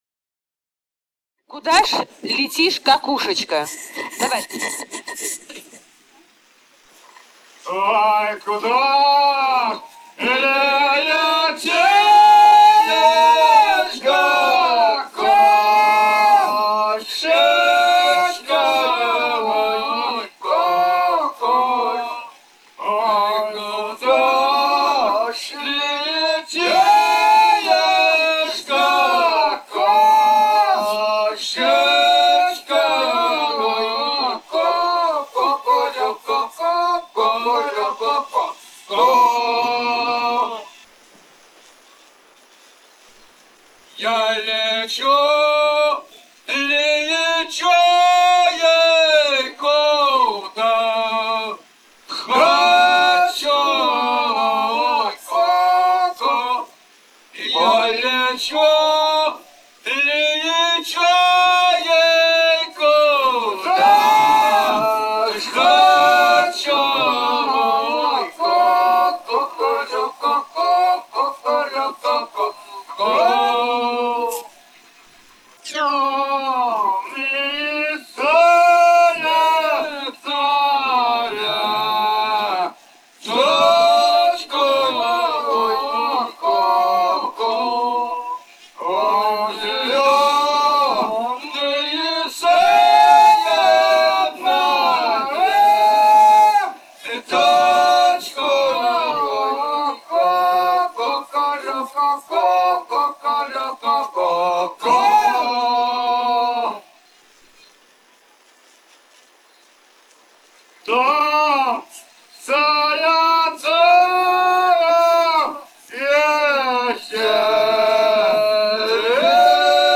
полевые материалы
Бурятия, с. Желтура Джидинского района, 1966 г. И0904-02